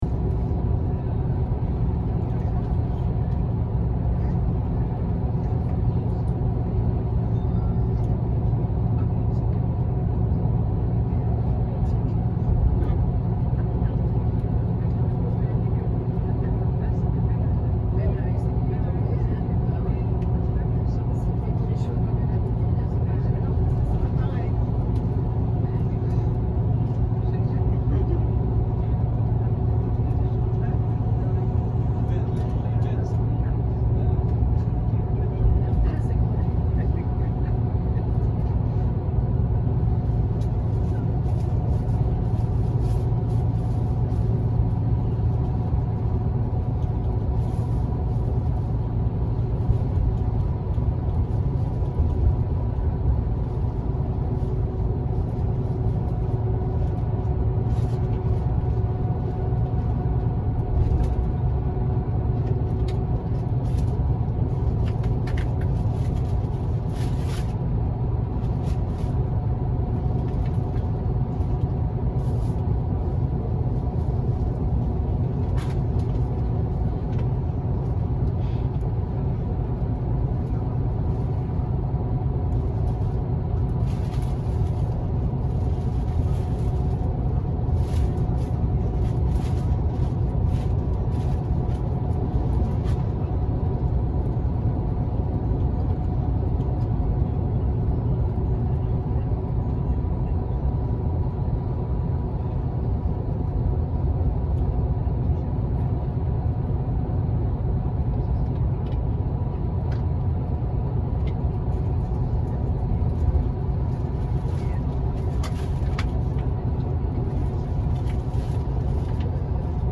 Звук автобуса скачать
Звук поездки в автобусе, шум салона, разговоры пассажиров. Езда по трассе
zvuk-avtobusa-na-trasse.mp3